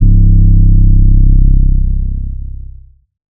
TS - 808 (2).wav